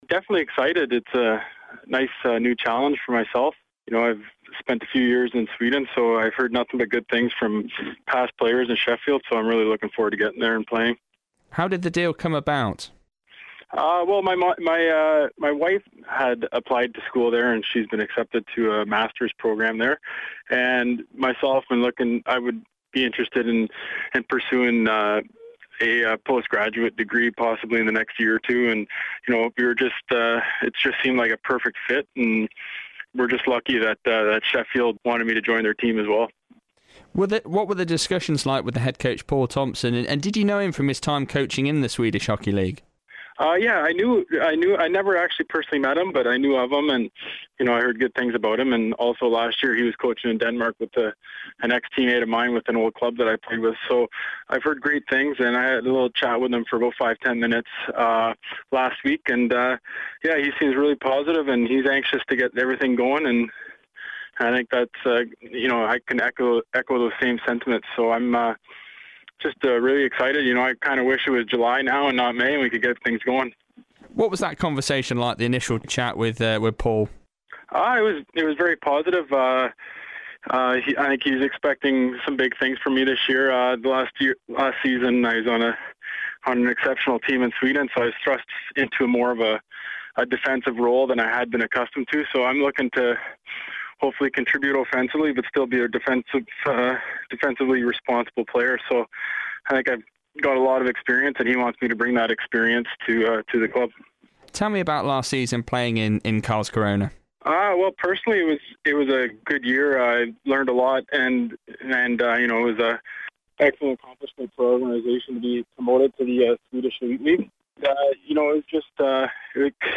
Speaking to BBC Radio Sheffield's